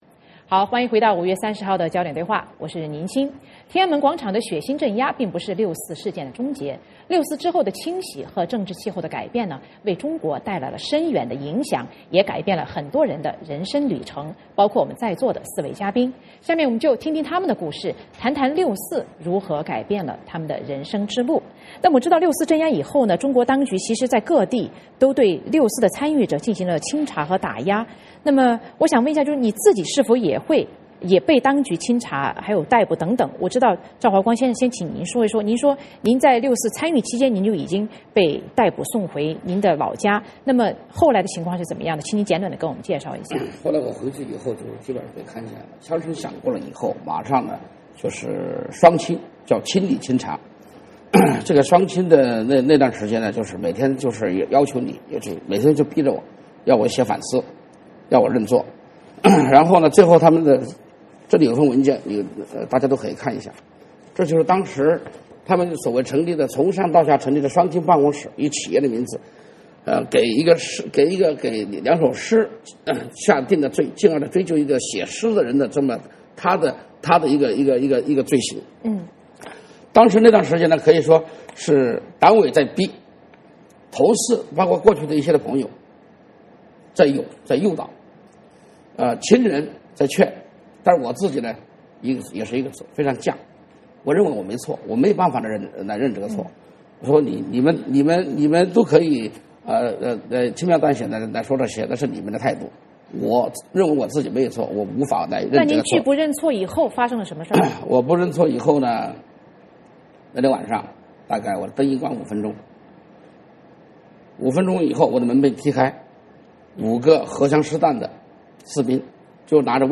六四之后的政治清洗和政治气候的改变，为中国带来了深远的影响，也改变了许多人的人生旅程。 今天的焦点对话我们请到几位二十五年前经历六四的朋友，请他们探讨六四如何改变了他们的人生旅程。